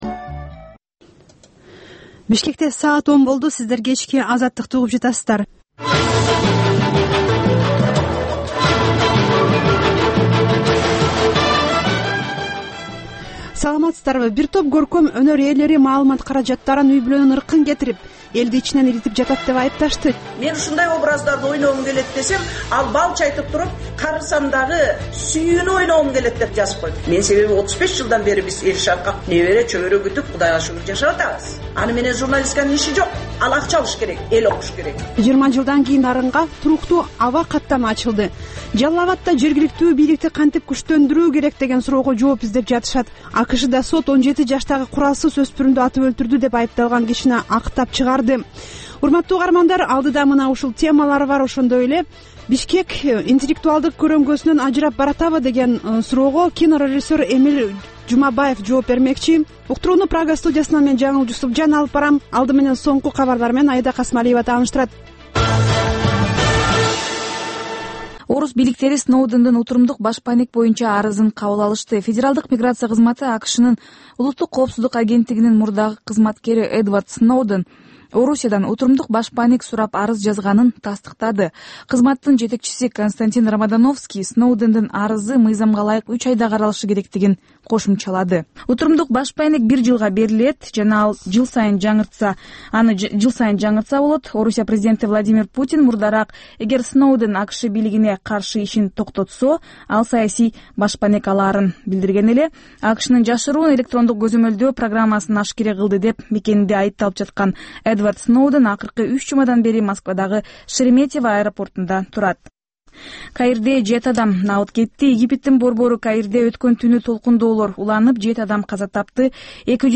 Кечки 10догу кабарлар